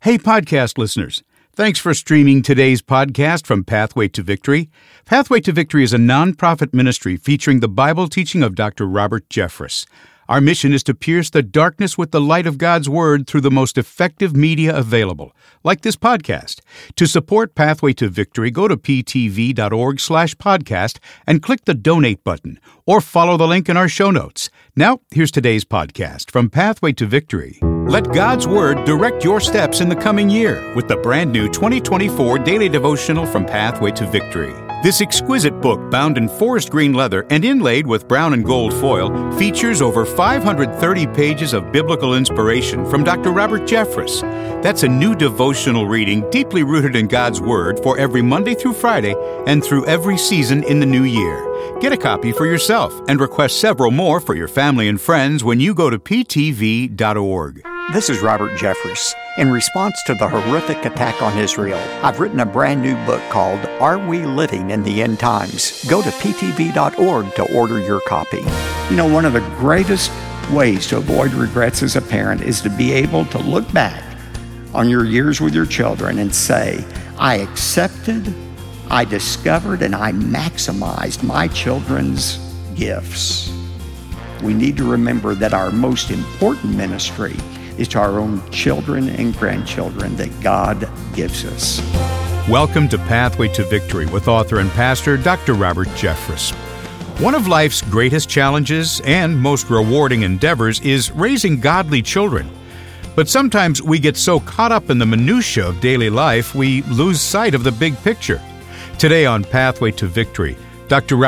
Raising godly children is one of life's greatest challenges and most rewarding endeavors, but it's easy to lose sight of the big picture in the daily grind. Dr. Robert Jeffress offers guidance on how to avoid regrets as a parent and prioritize family ministry.